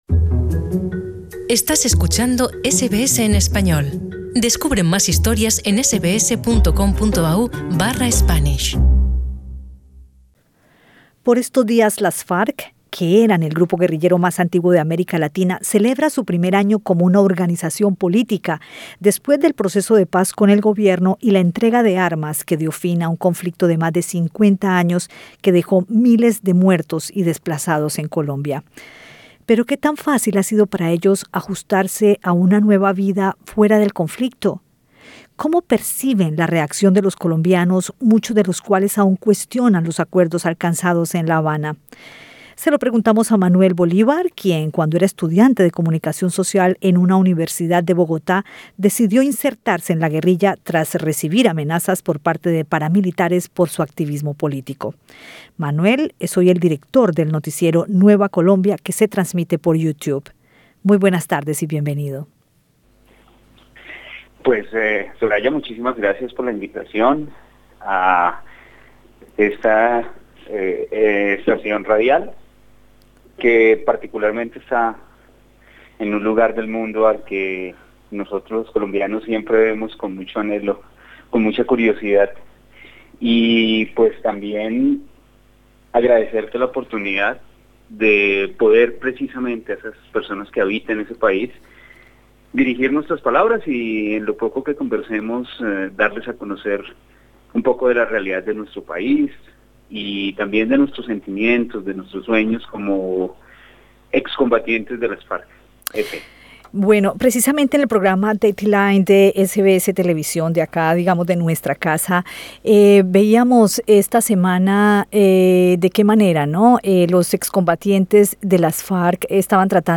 Escucha el podcast con la entrevista